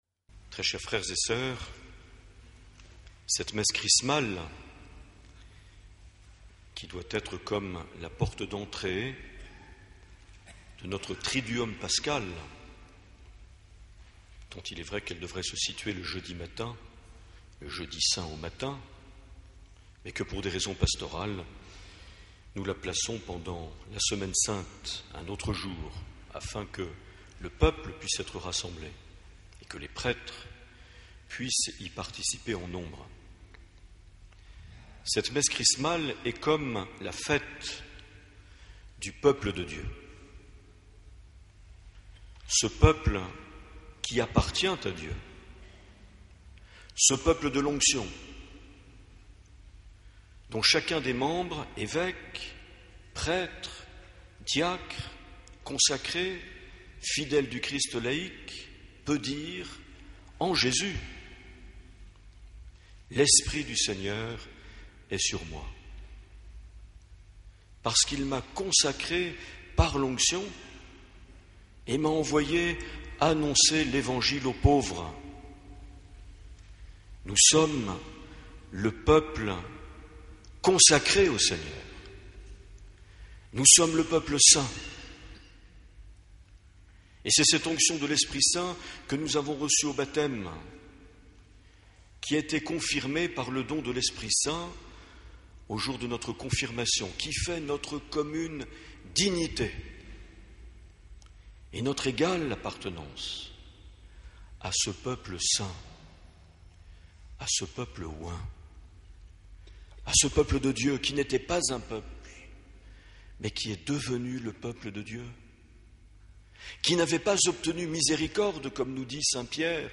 25 mars 2013 - Cathédrale d’Oloron - Messe Chrismale
Les Homélies
Une émission présentée par Monseigneur Marc Aillet